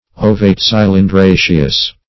Search Result for " ovate-cylindraceous" : The Collaborative International Dictionary of English v.0.48: Ovate-cylindraceous \O"vate-cyl`in*dra"ceous\, a. Having a form intermediate between ovate and cylindraceous.